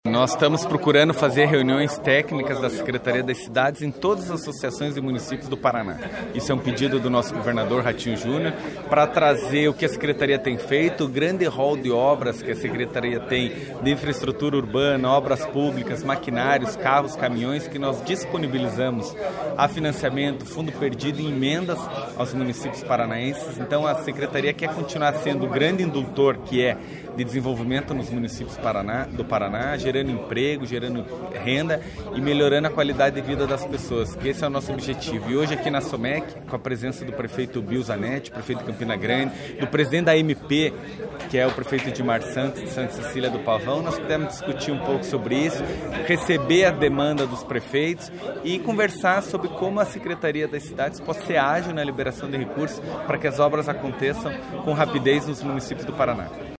Pimentel falou para prefeitos e outras lideranças, na manhã desta quinta-feira, 4, durante a reunião da Associação dos Municípios da Região Metropolitana de Curitiba (Assomec), no Parque Newton Puppi, em Campo Largo.